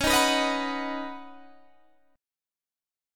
C#7sus2sus4 chord